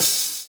DISCO 11 OH.wav